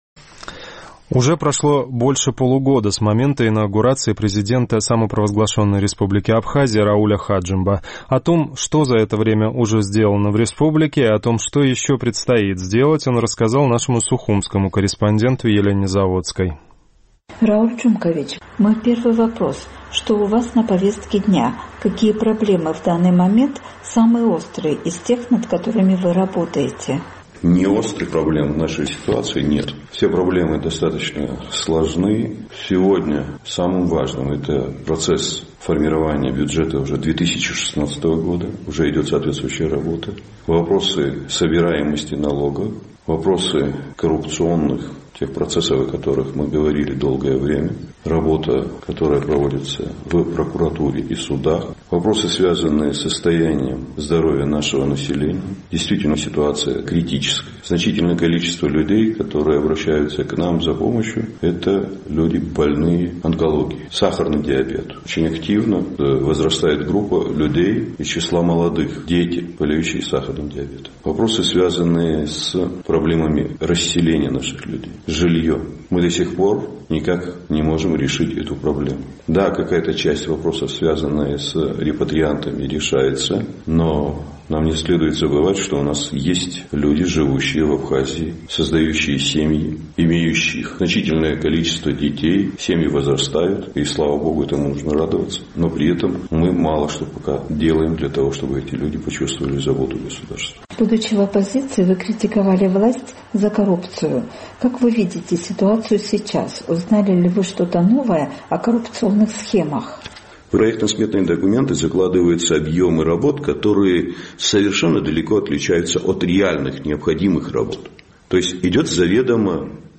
Прошло больше полугода с момента инаугурации абхазского президента Рауля Хаджимба. В интервью